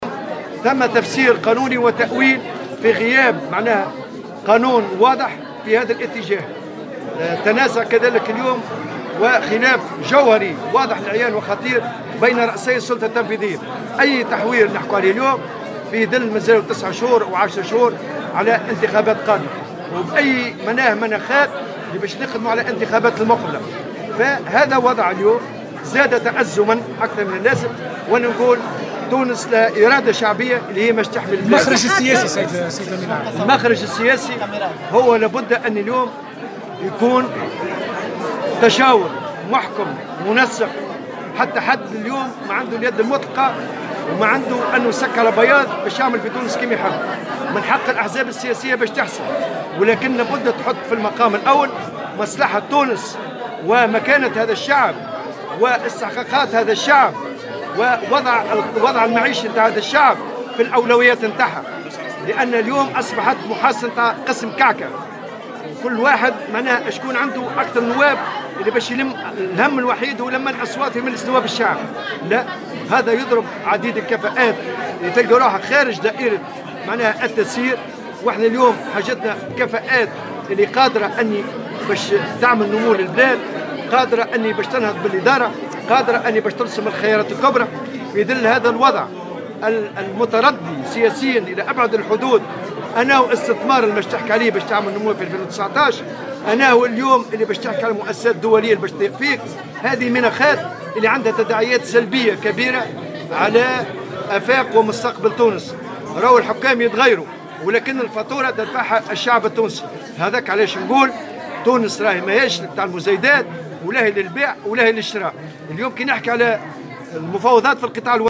جاء ذلك في كلمة ألقاها الطبوبي خلال تجمع عمالي لأعوان وزارة التجهيز بتونس العاصمة استعدادا لإضراب عام قرره الاتحاد بقطاع الوظيفة العمومية يوم 22 نوفمبر 2018.